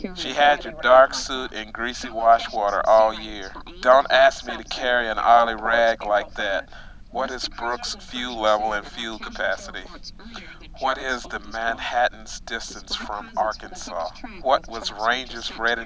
However, inbound signals may interfere with outbound signals and create an annoying positive feedback during communications.
Actual data were recorded in helmet.
The male voice is the outbound signal and the female voice is the inbound signal, which interferes with the outbound signal.